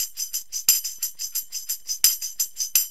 TAMB LP 88.wav